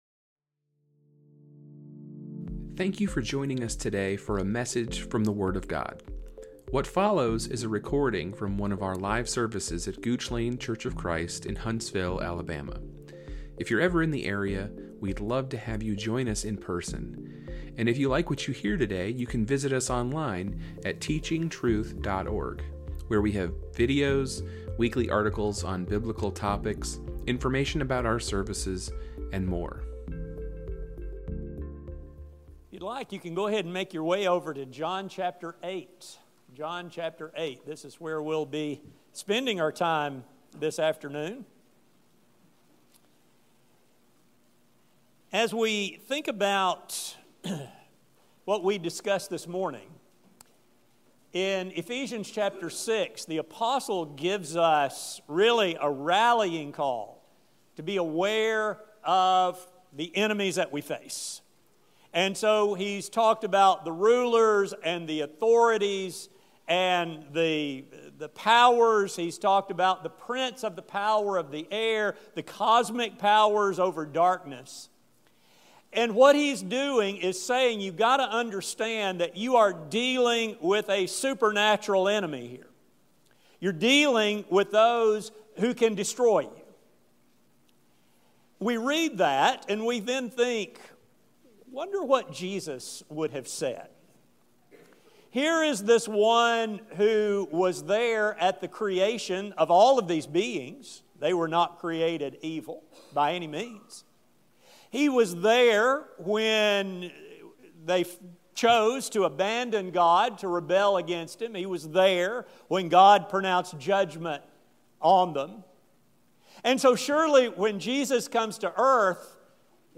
This sermon will center on Jesus' confrontation with the Jews (as recorded in John 8), which ultimately led Him to declare that their father is the devil.